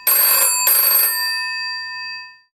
Phone Ring